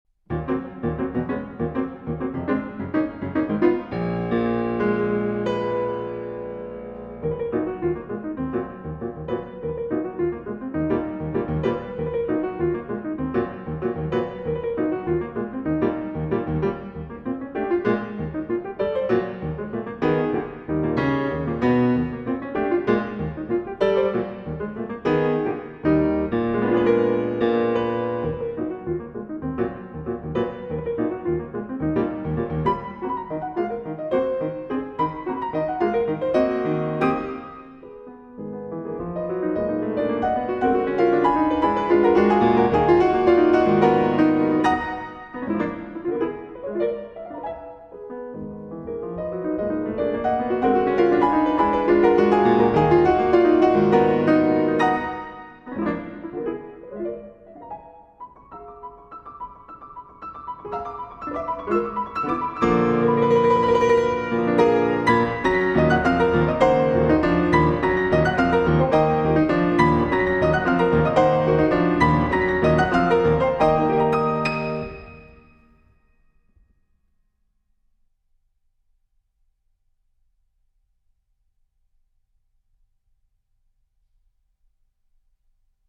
exuberant